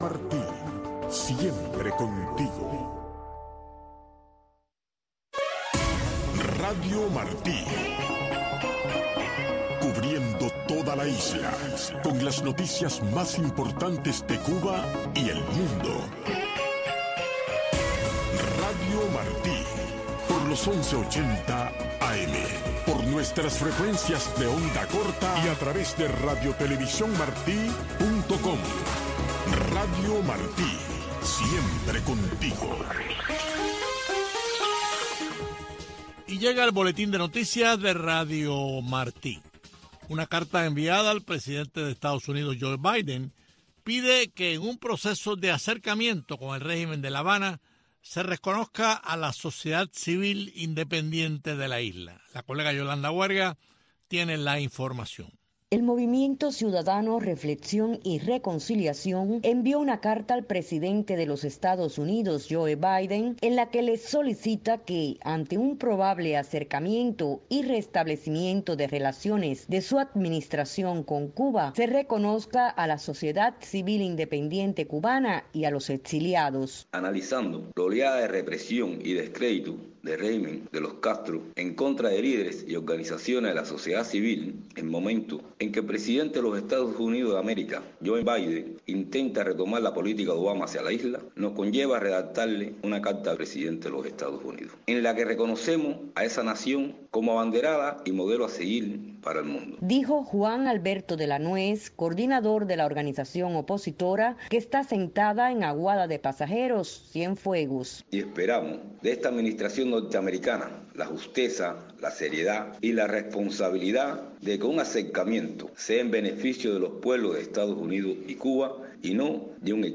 Un desfile de éxitos de la música pop internacional, un conteo regresivo con las diez canciones más importantes de la semana, un programa de una hora de duración, diseñado y producido a la medida de los jóvenes cubanos.